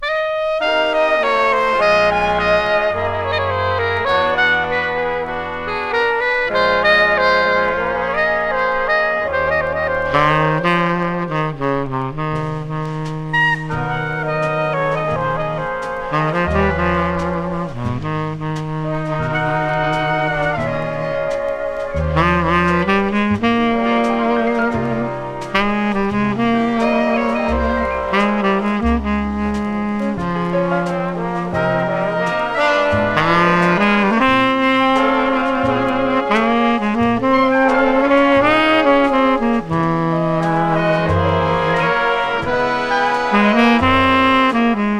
本盤は、活動の指針とも思えるビッグ・バンド・ジャズで聴かせます。
粋とも思えるムードがなんとも心地よく音から溢れ、艶やかな音、彩り豊かなアレンジも素敵。
Jazz, Big Band　USA　12inchレコード　33rpm　Mono